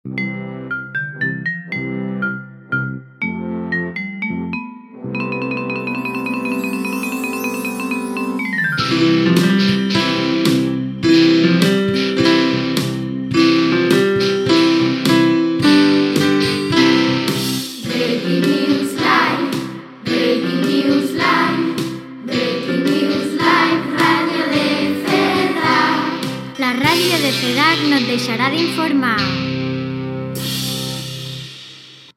Sinonia i indicatiu cantat de la ràdio